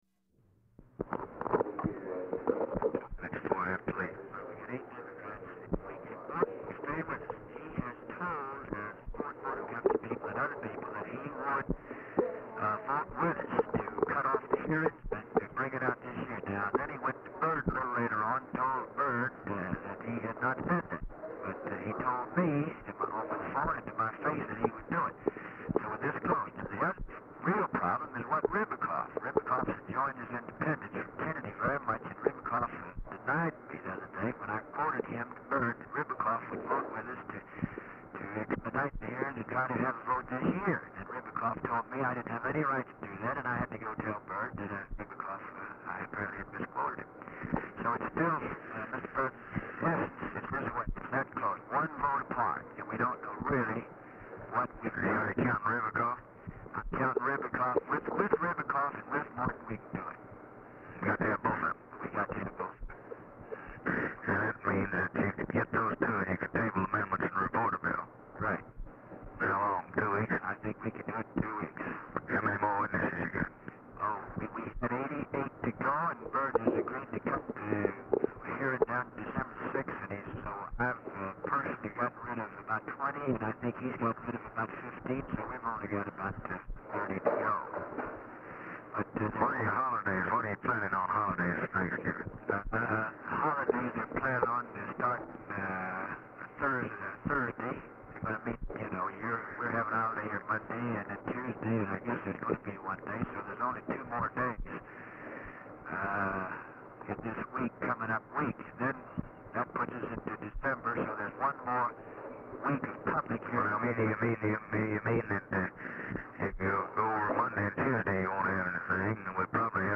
Telephone conversation # 17, sound recording, LBJ and GEORGE SMATHERS, 11/23/1963, 2:10PM | Discover LBJ
DAT RECORDING MADE FROM REEL-TO-REEL TAPE RECORDED FROM BELT TO IMPROVE SOUND
Format Dictation belt
Specific Item Type Telephone conversation